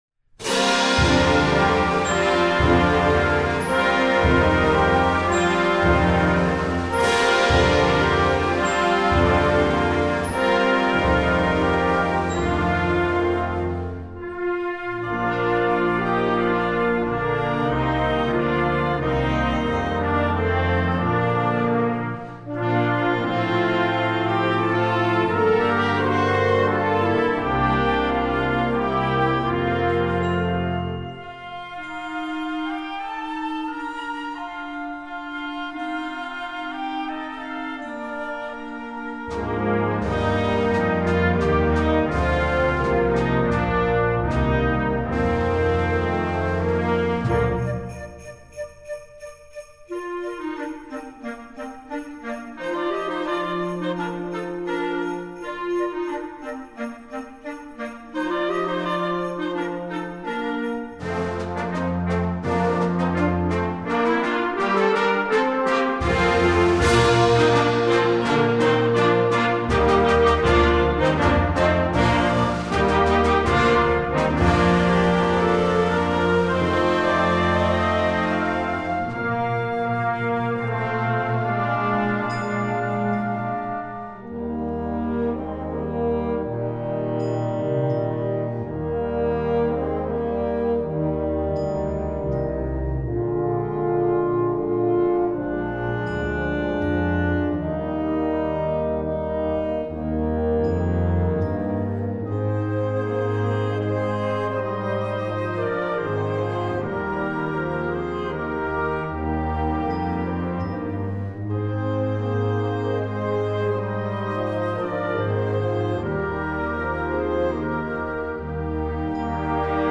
Instrumentation: concert band
instructional, children